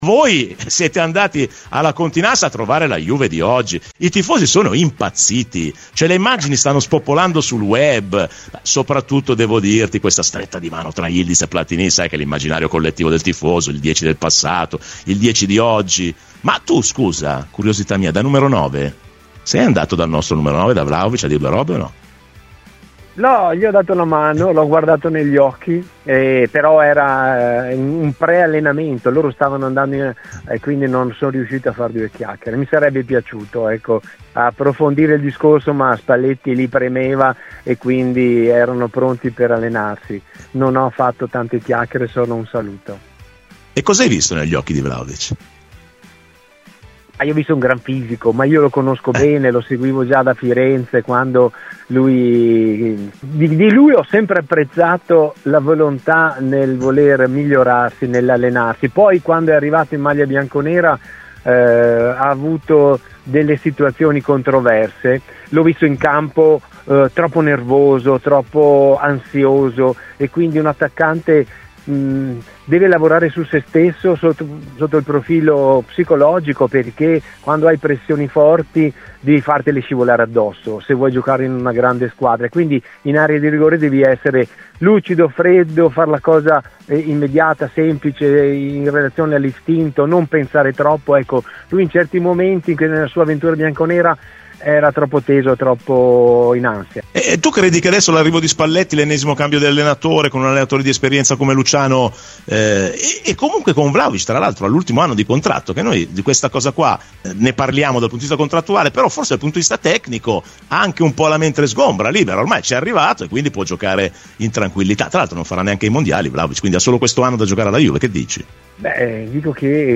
Aldo Serena ha parlato di Juve a Radio Bianconera: tra i temi trattati dall'ex attaccante bianconero Vlahovic, Yildiz, Platini e Spalletti